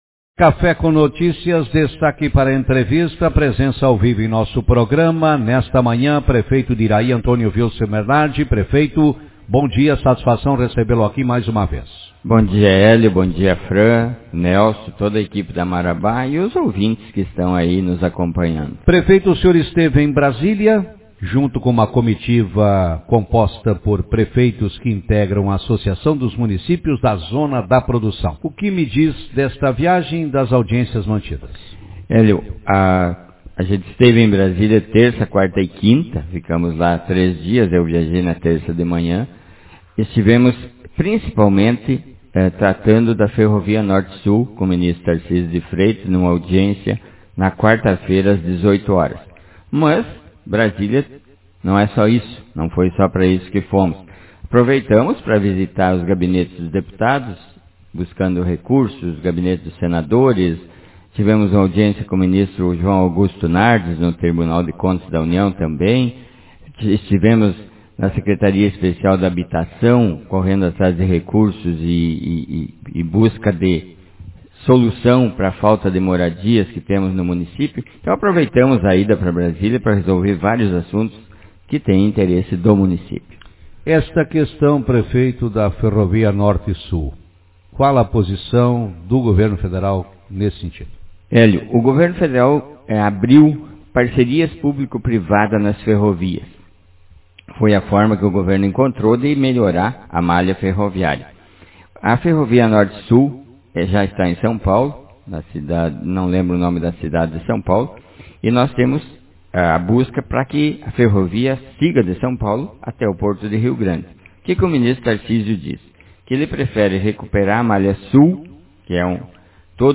Na capital federal com os demais Prefeitos participou de audiência com o ministro dos Transportes pleiteando a implantação de ferrovia ligando o Rio Grande do Sul com o centro do País. Na manhã de hoje, no programa Café com Notícias, o prefeito falou sobre este e outros assuntos.